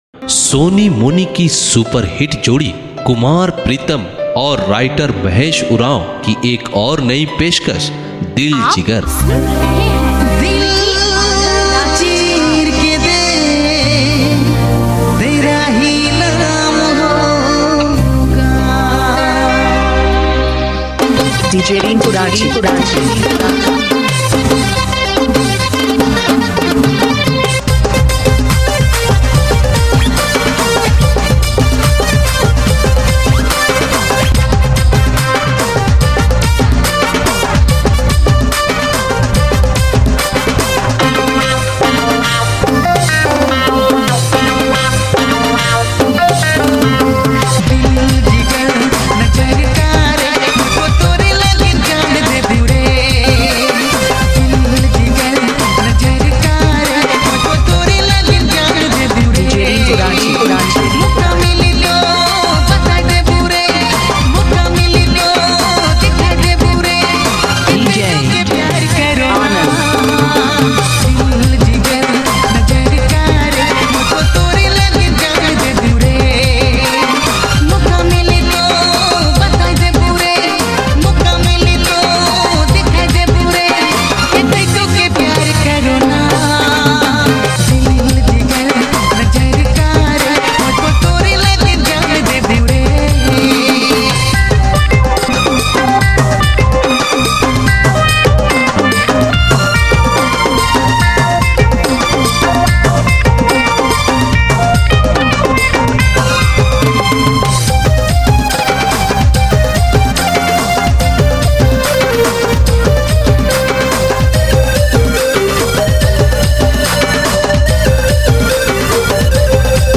Nagpuri dj song